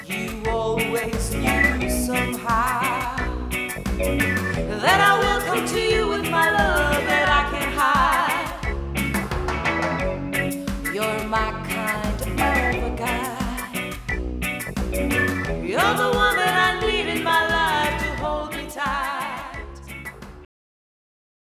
Guitares: Électrique / Acoustique
Piano / Orgue